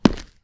concrete_step_2_-05.wav